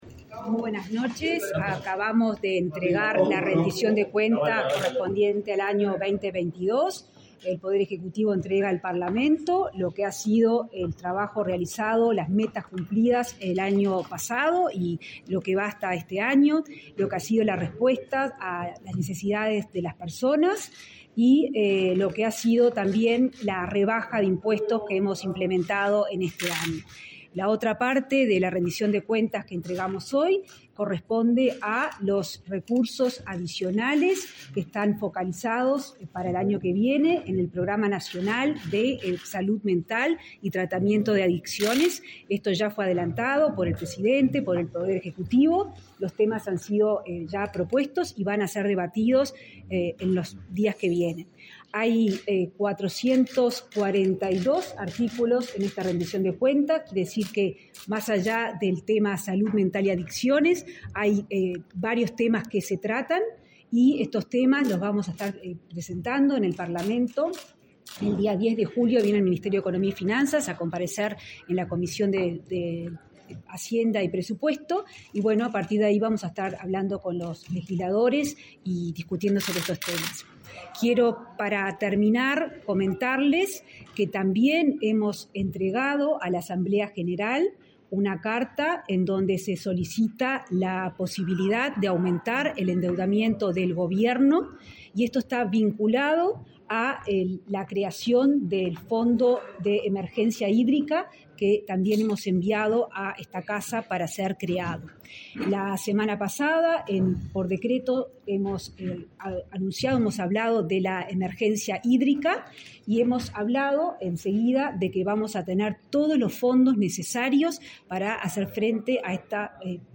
Palabras de la ministra de Economía y Finanzas, Azucena Arbeleche
Palabras de la ministra de Economía y Finanzas, Azucena Arbeleche 01/07/2023 Compartir Facebook X Copiar enlace WhatsApp LinkedIn El Gobierno presentó, este 30 de junio, el proyecto de ley de Rendición de Cuentas ante el Parlamento. La ministra de Economía y Finanzas, Azucena Arbeleche, realizó declaraciones a la prensa.